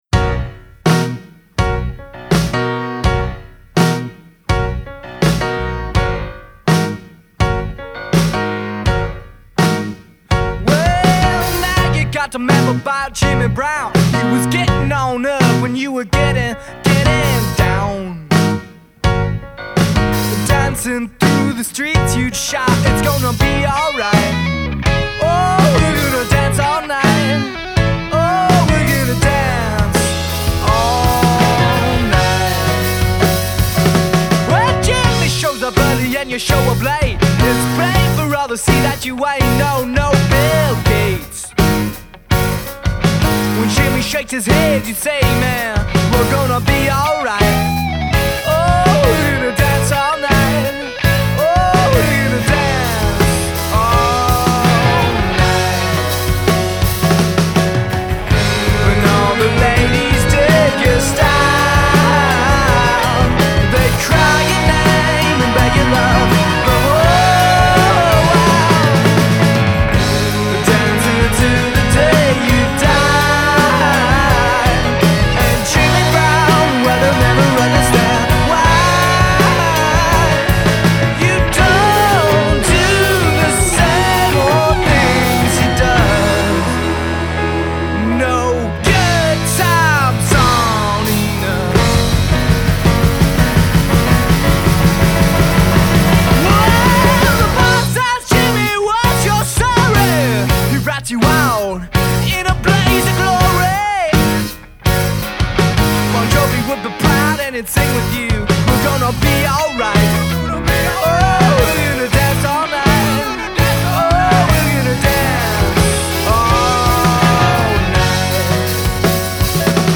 Love this happy track.